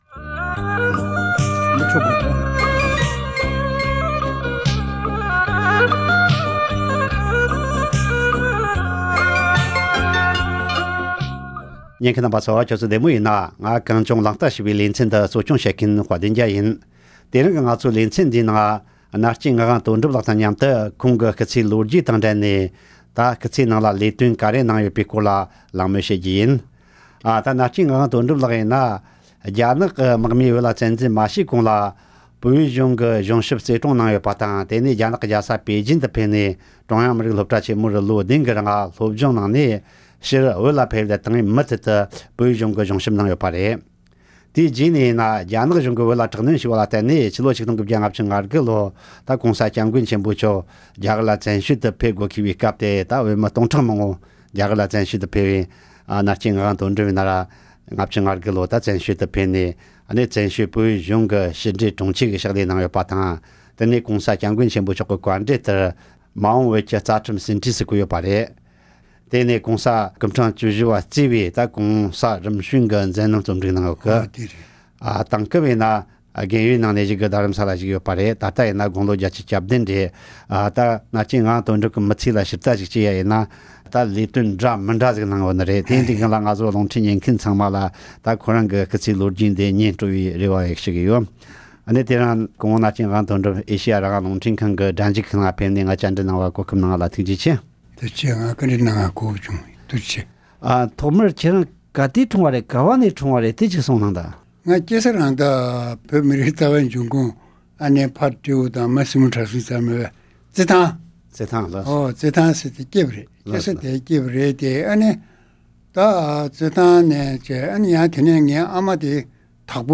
ཁོང་གི་སྐུ་ཚེའི་ལོ་རྒྱུས་སྐོར་ལ་གླེང་མོལ།